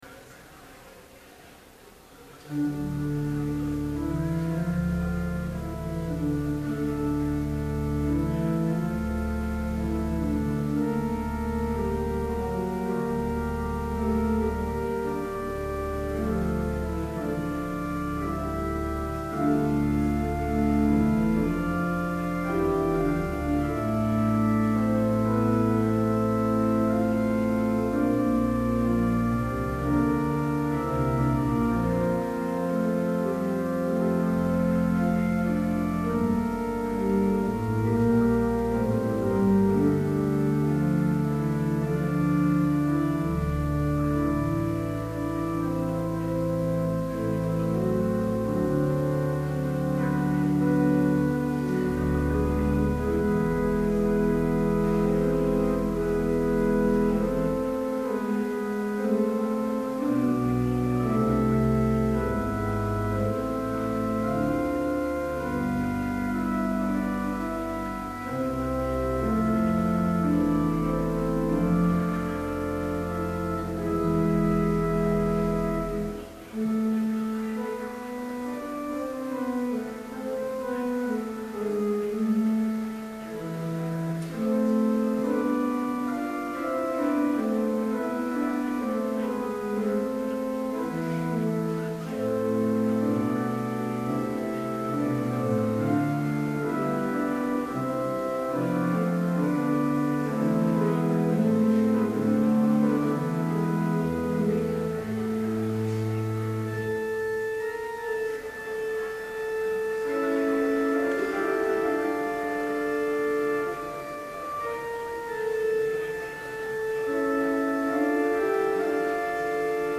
Complete service audio for Chapel - February 23, 2012